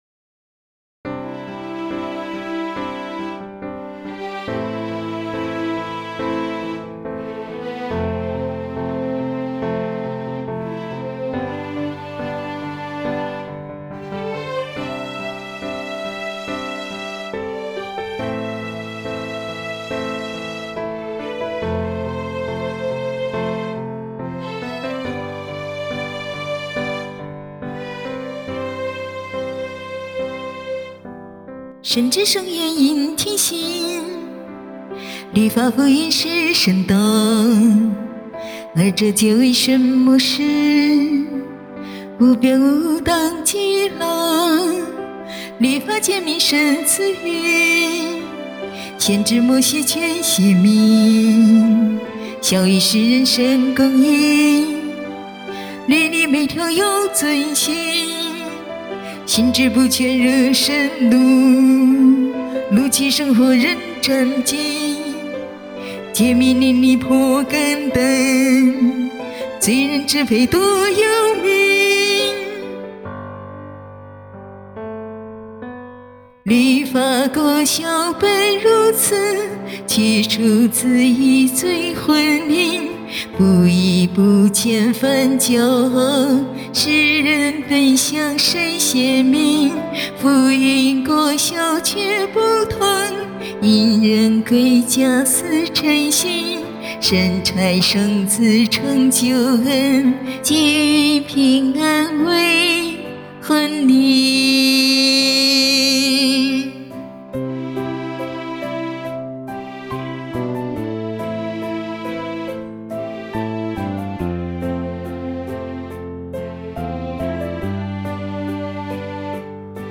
献唱/律法福音区别（五）产生果效不同